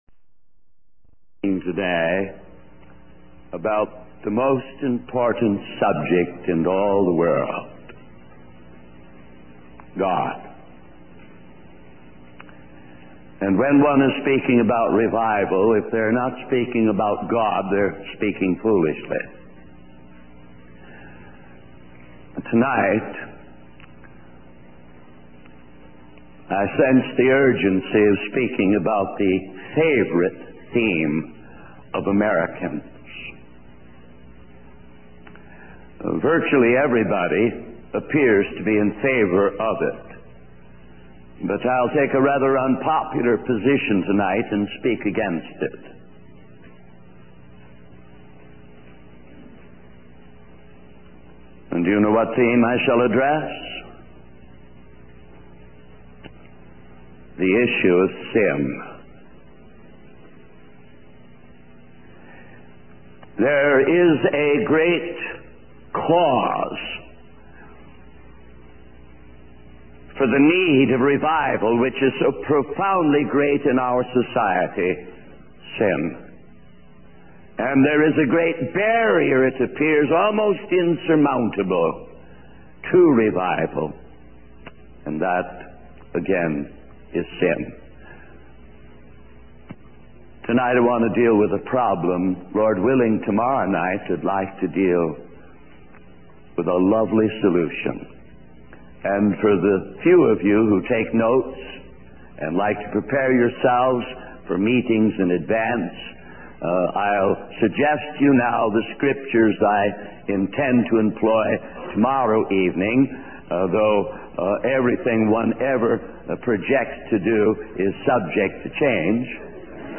This sermon delves into the urgent need to address the issue of sin as a barrier to revival, emphasizing the importance of mastering sin through Christ. It explores the story of Cain and Abel, highlighting the significance of faith and righteousness in offerings.